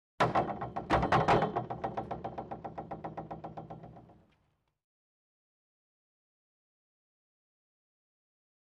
Bounce On Diving Board With Ring Off.